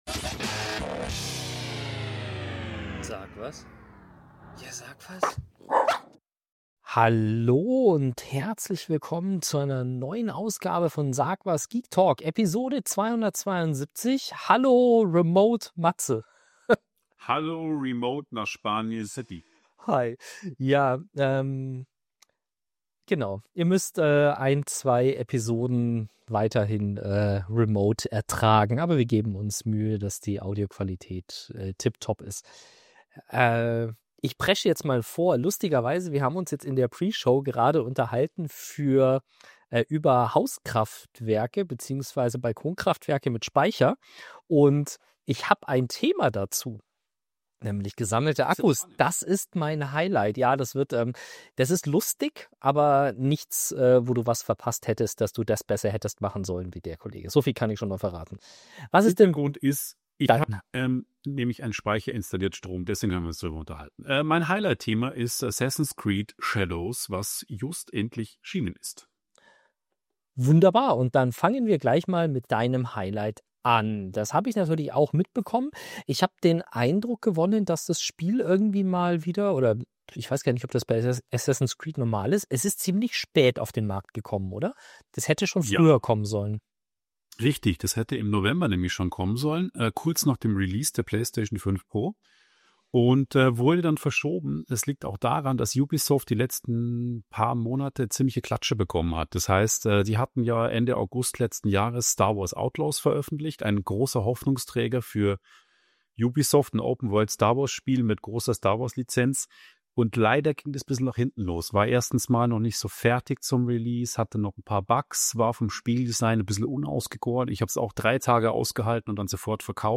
Neuerungen aus den Bereichen TV, Video on Demand, Computerspiele, Konsolen, Mobilfunk und Computer Sicherheit werden aufbereitet und allgemeinverständlich erklärt. Die Sendung wird durch musikalische Themen (Münchner Musikszene oder besonders erfolgreich im Internet) aufgelockert.
Sendungsdauer: ca. 50-60 Minuten Rhythmus: Alle zwei Wochen Format: Experten-Gespräch mit Einspielern.